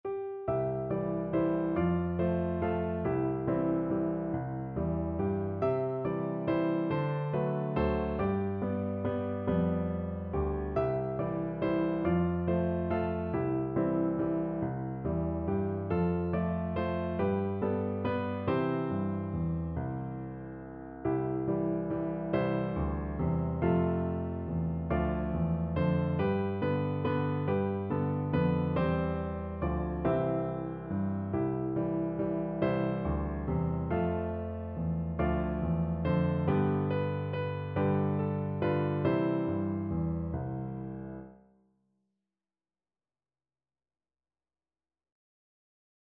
Piano Four Hands (Piano Duet)
3/4 (View more 3/4 Music)
Scottish